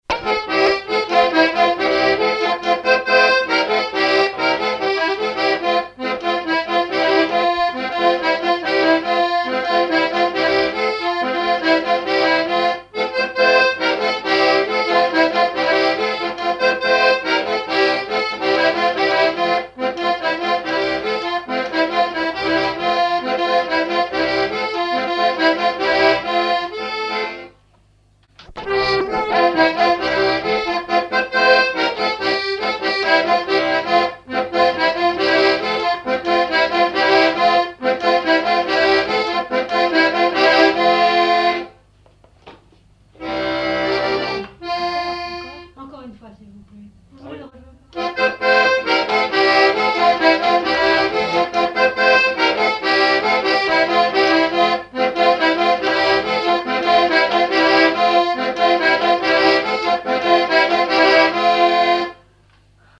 Localisation Noirmoutier-en-l'Île (Plus d'informations sur Wikipedia)
Fonction d'après l'analyste danse : branle ;
Genre brève
Catégorie Pièce musicale inédite